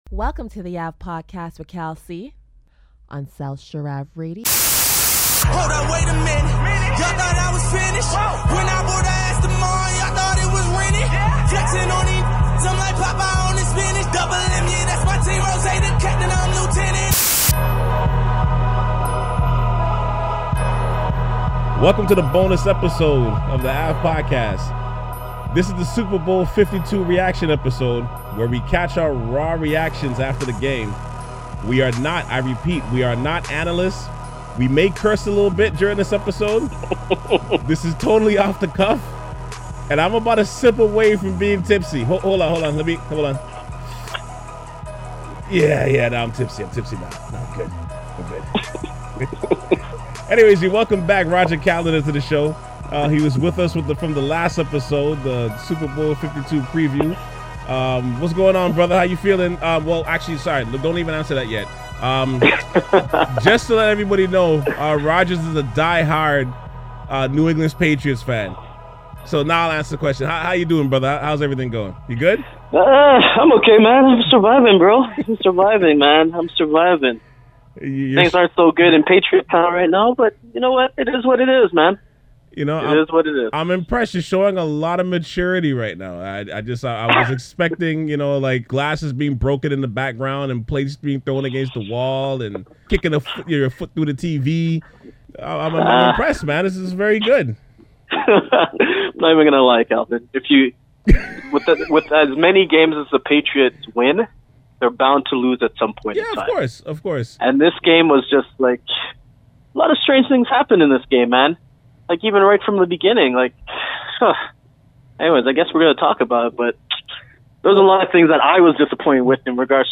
Today on The Ave Podcast, we have our 1st uncut Super Bowl post game reaction podcast.
*** DISCLAIMER: THIS PODCAST EPISODE CONTAINS EXPLICIT LANGUAGE. ***